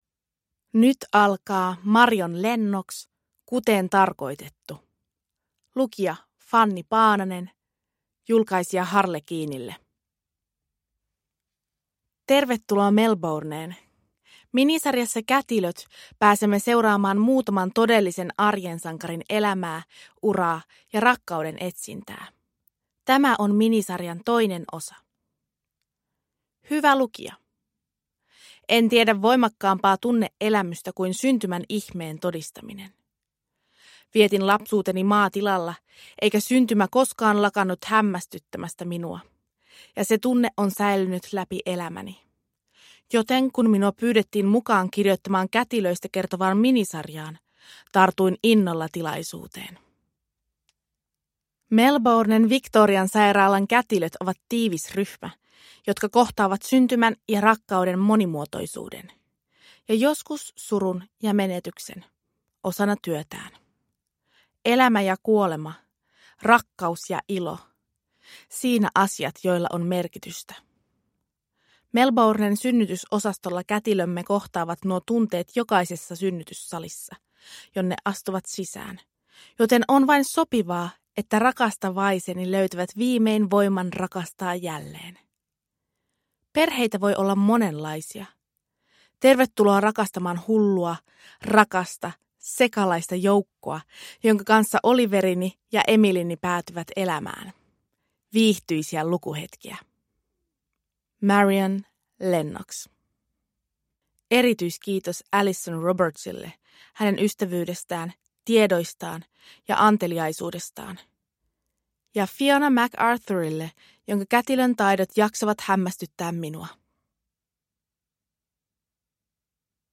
Kuten tarkoitettu – Ljudbok – Laddas ner